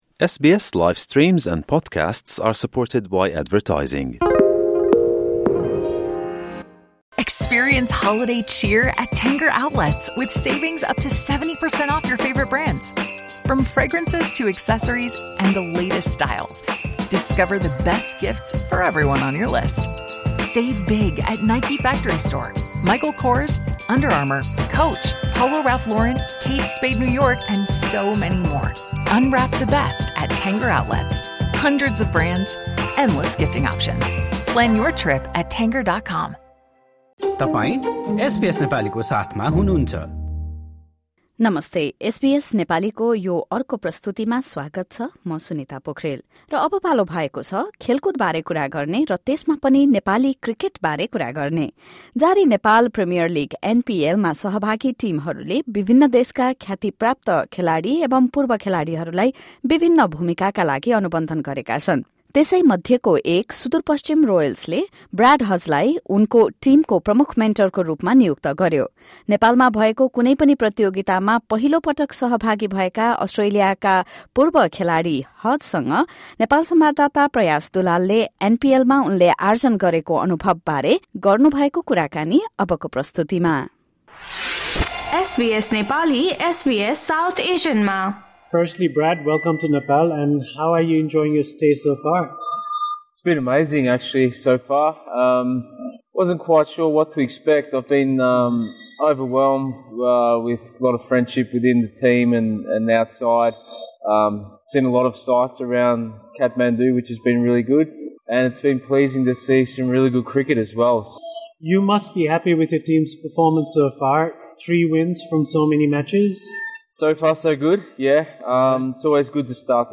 Former Australian cricketer Brad Hodge, the chief mentor of Sudurpaschim Royals in the ongoing Nepal Premier League (NPL), has shared his experiences of staying in Nepal. Hodge spoke to SBS Nepali about his first-ever Nepali tournament experience, the future of Nepali cricket, and even his culinary adventures.